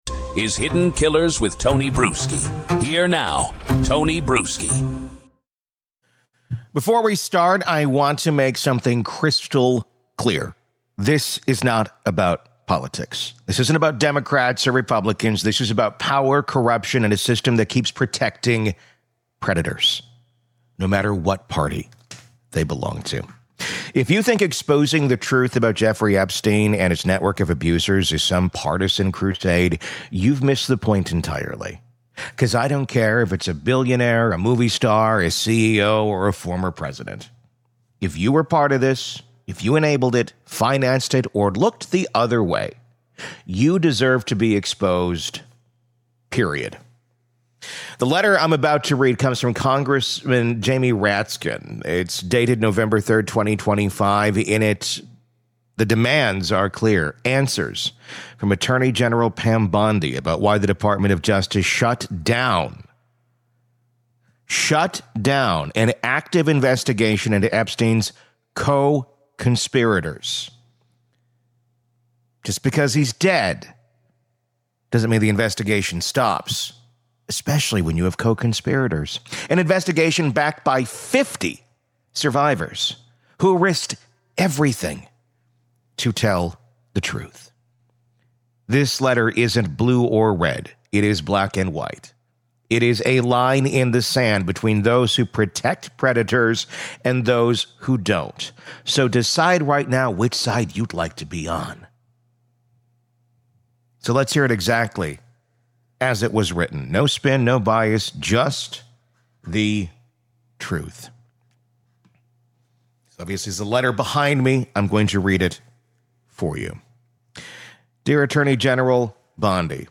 Tonight, we’re not summarizing — we’re reading it.